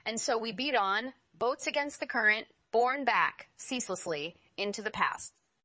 to23oise-tts - (QoL improvements for) a multi-voice TTS system trained with an emphasis on quality